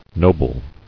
[no·ble]